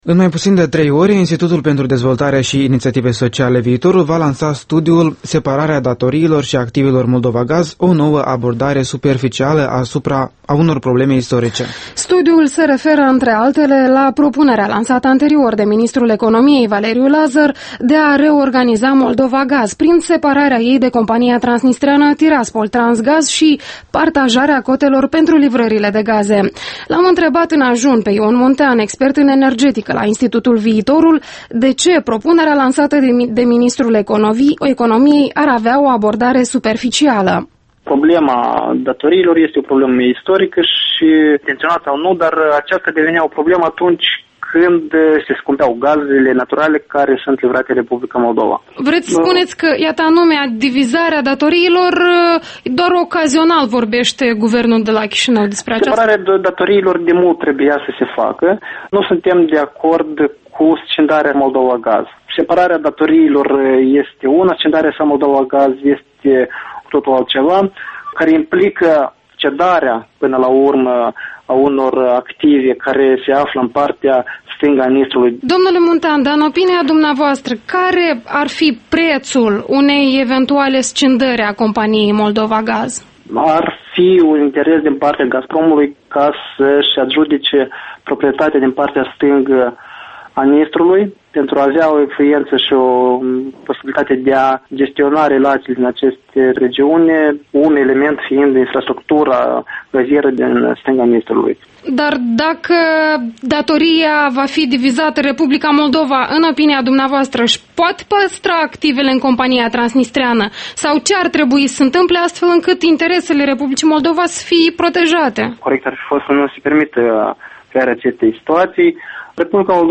Interviul matinal Europa Liberă